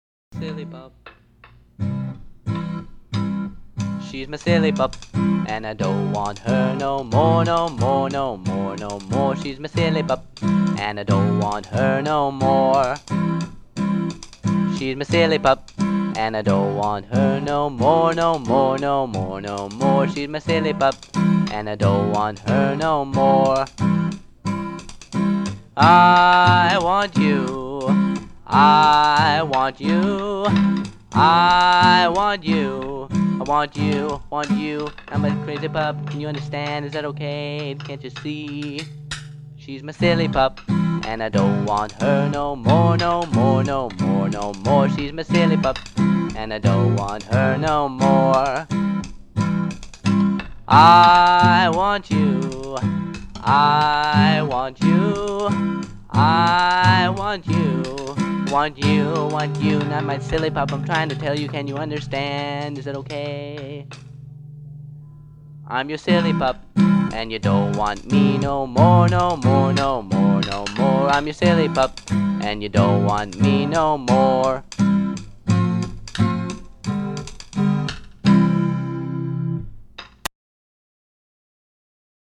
I’ve always loved this song- it’s playful, fun, and quite frankly, Silly.
The percussion is me using drumsticks on the wooden case of my Bass Amp…